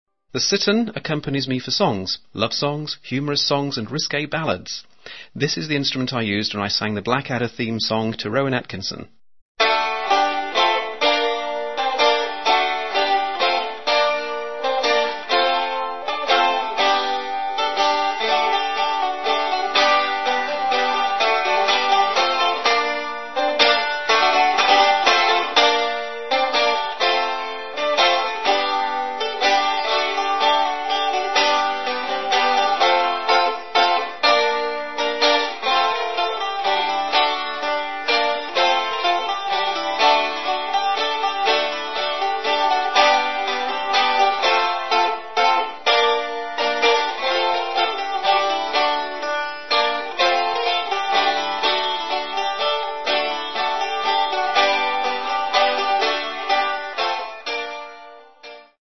An Elizabethan gentleman specialising in the stringed instruments of the renaissance and early baroque periods.